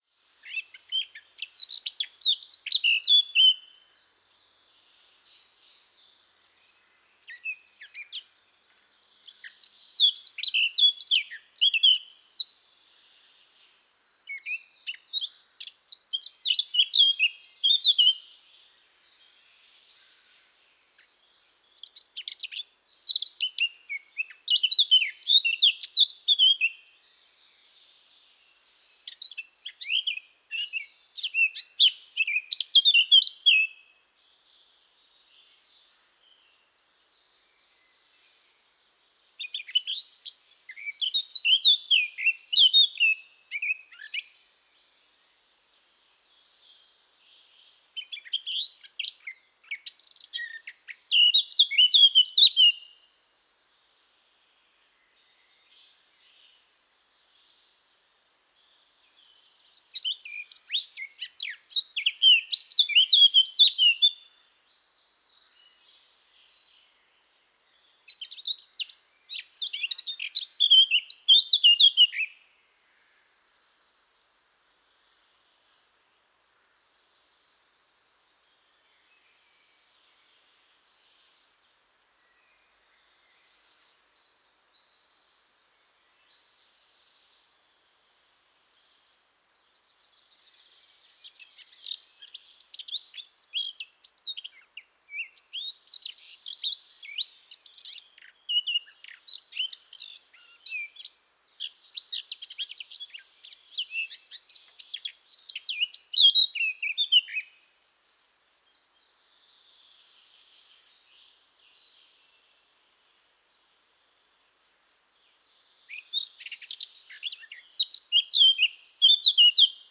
XC656559-toutinegra-de-barrete-Sylvia-atricapilla